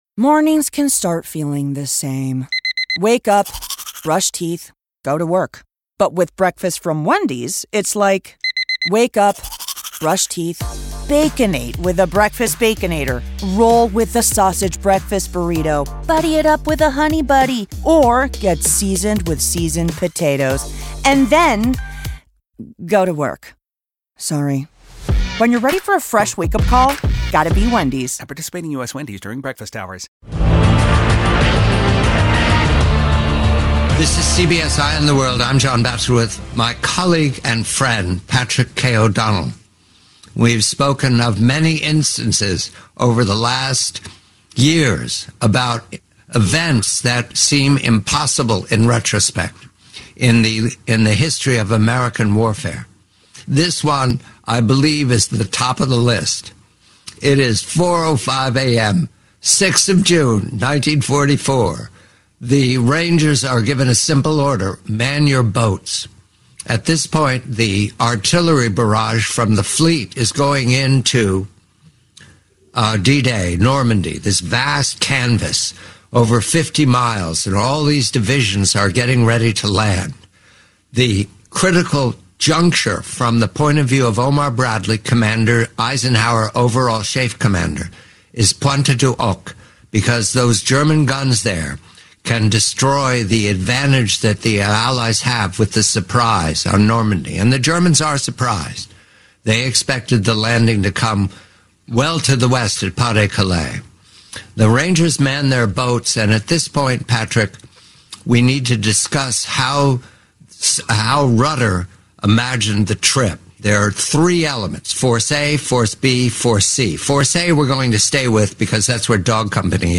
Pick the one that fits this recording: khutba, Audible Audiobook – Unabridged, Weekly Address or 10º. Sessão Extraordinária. Audible Audiobook – Unabridged